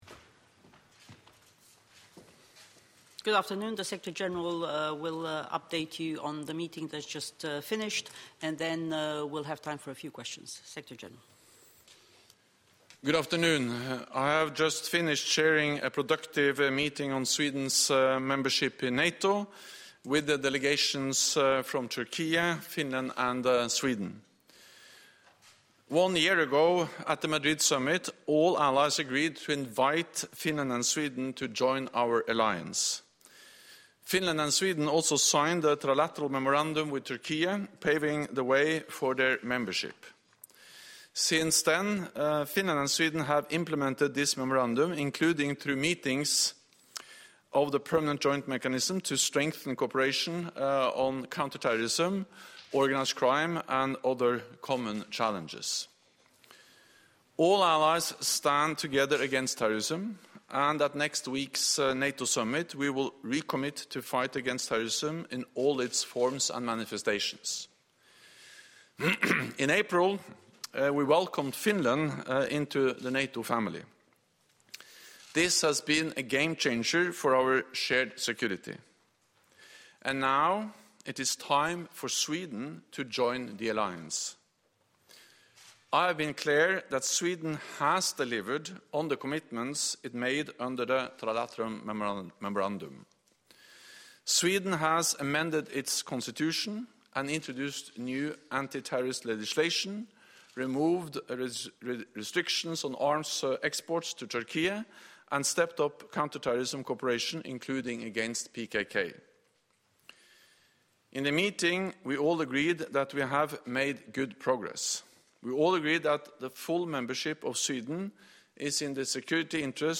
Jens Stoltenberg NATO Presummit Presser on Turkey-Sweden Agreement (transcript-audio-video)